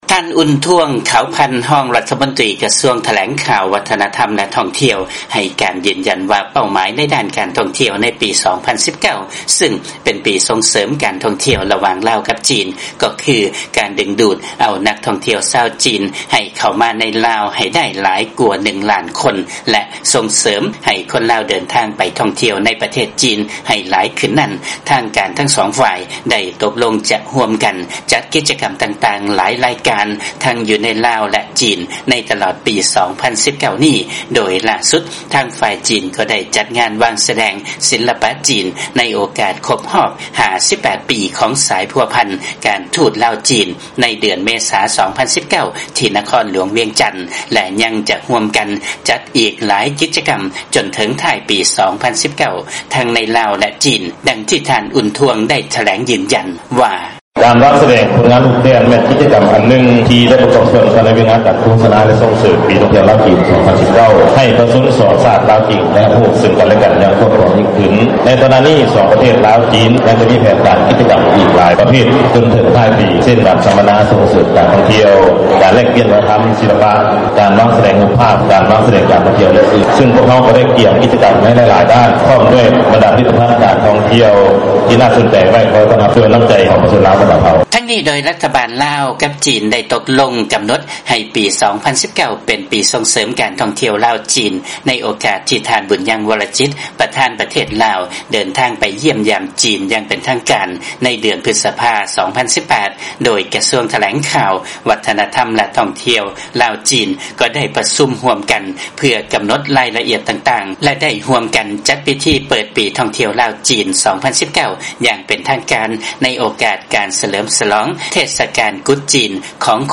ຟັງລາຍງານ ທາງການ ລາວ ແລະ ຈີນ ຈະຮ່ວມກັນ ຈັດກິດຈະກຳຕ່າງໆ ໃນຕະຫຼອດປີ 2019 ເພື່ອບັນລຸເປົ້າໝາຍ ປີທ່ອງທ່ຽວ